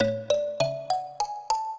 mbira
minuet5-3.wav